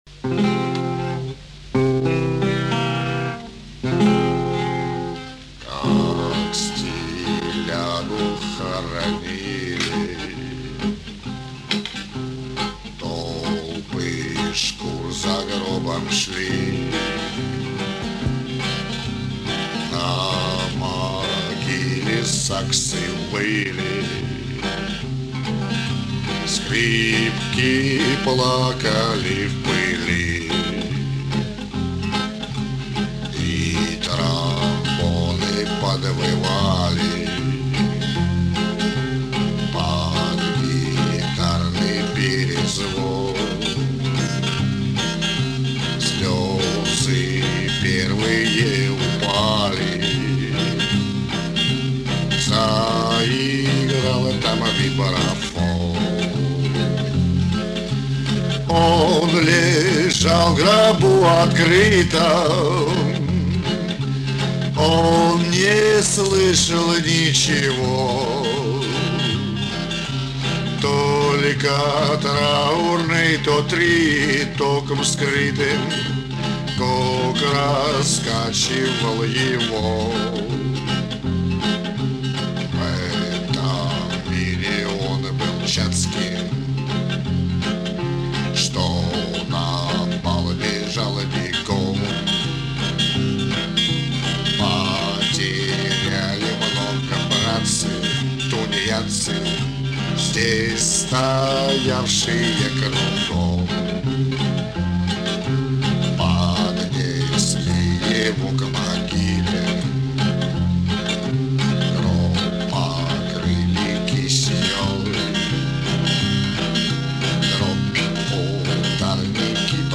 Классика кантри